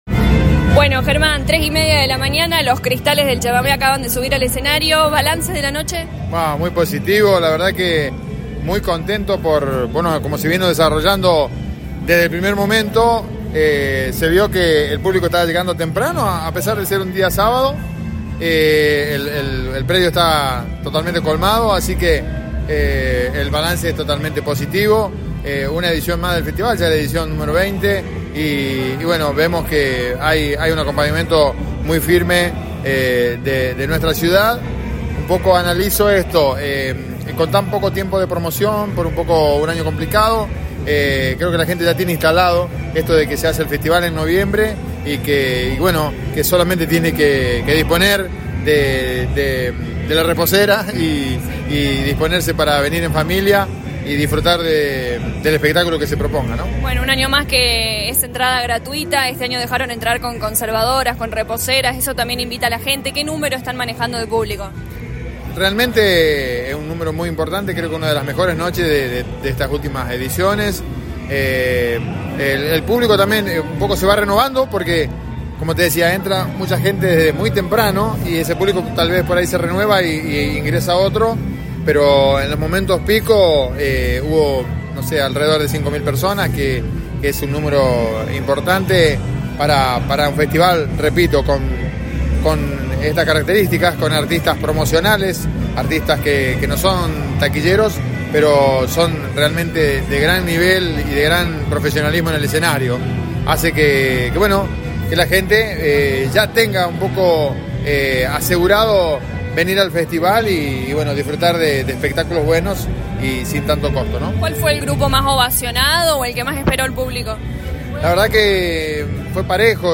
Sobre el cierre de la noche del sábado, el secretario de Cultura Germán Argañaráz dialogó con LA RADIO 102.9 FM e hizo un balance de la 20° Edición del Festival Nacional del Humor y la Canción.
Muy emocionado y contento agradeció una vez más el acompañamiento del público y también el trabajo de todos los colaboradores.